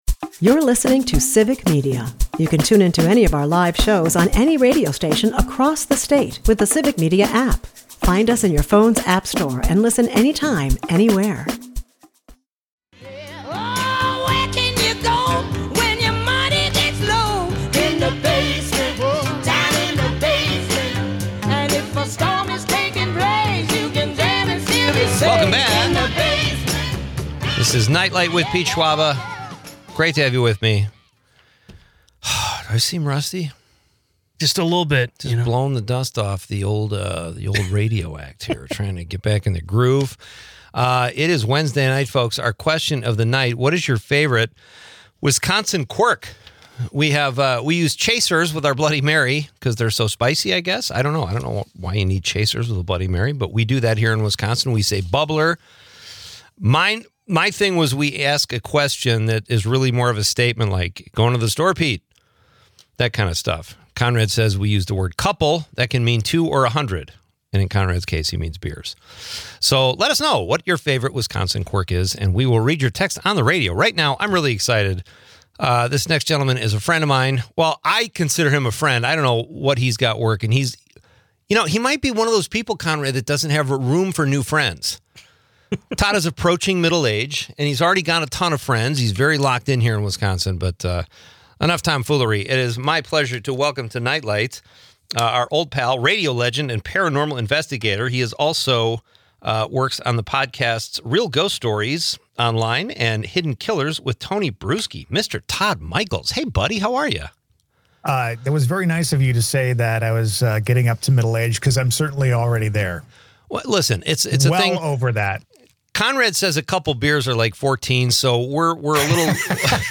The episode explores nostalgic elements like landline phones and fedoras, while listeners share quirky memories of Mad Magazine parodies and penny beer pitchers.